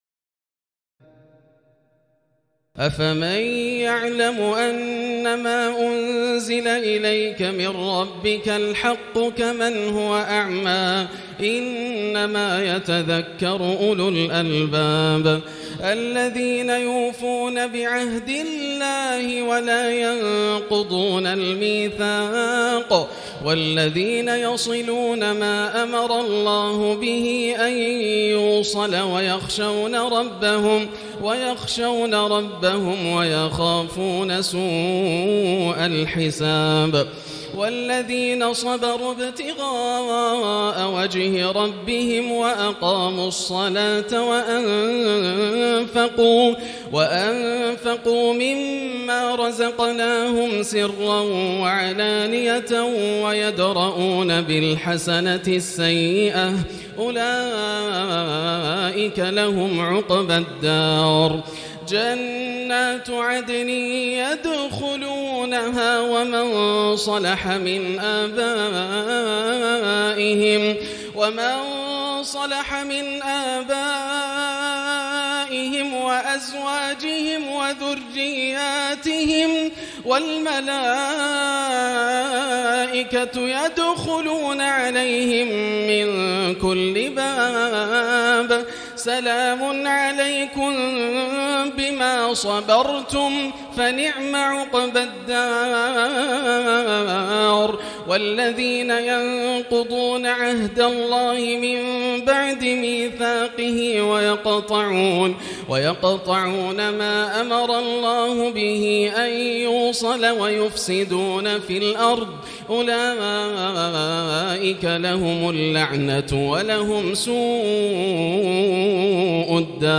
تراويح الليلة الثانية عشر رمضان 1439هـ من سورتي الرعد (19-43) و إبراهيم كاملة Taraweeh 12 st night Ramadan 1439H from Surah Ar-Ra'd and Ibrahim > تراويح الحرم المكي عام 1439 🕋 > التراويح - تلاوات الحرمين